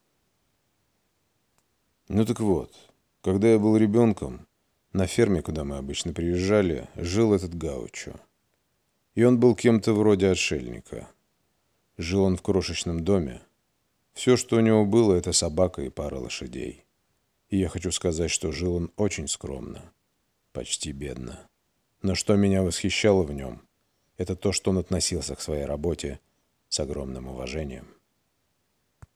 Проба голоса.wav
Пол Мужской